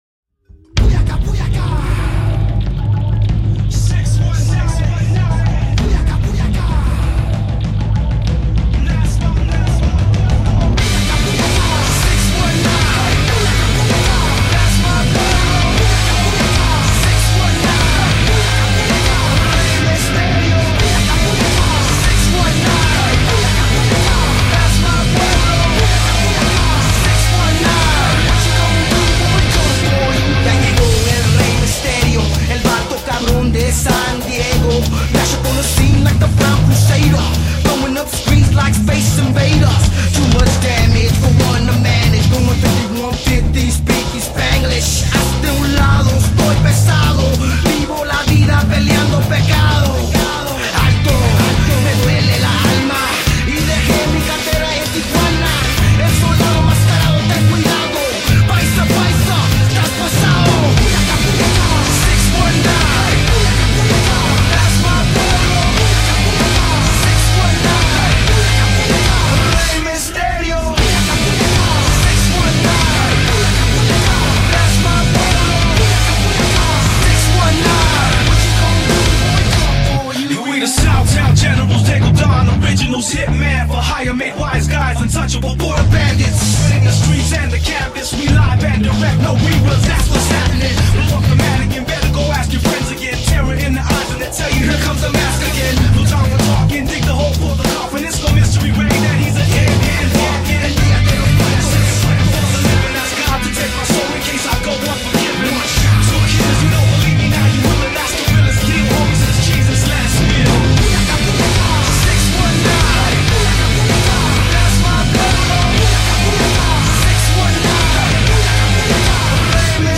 آهنگ ورود